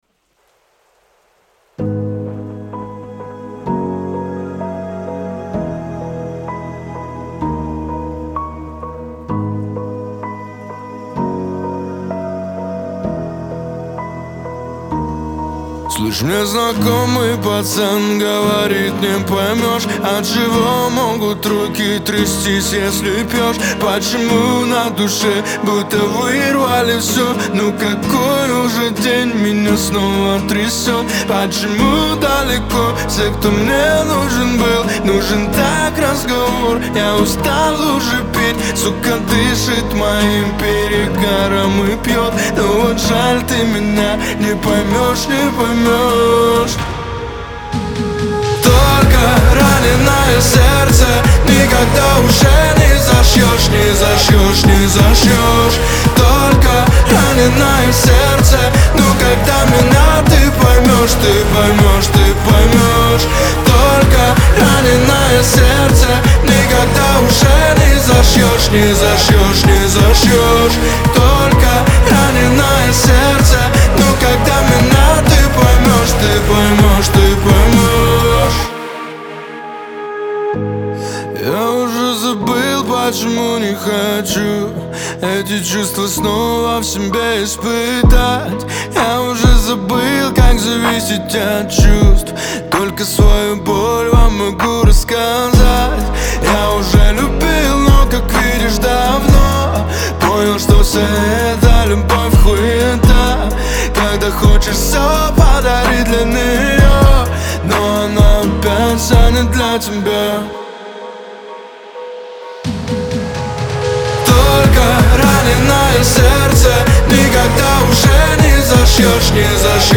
Настроение трека — меланхоличное, но с нотками надежды.